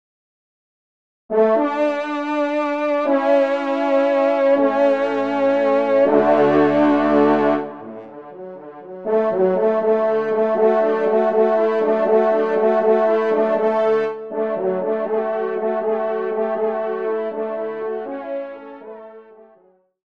Genre : Fantaisie Liturgique pour quatre trompes
Pupitre 3° Trompe